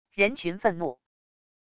人群愤怒wav音频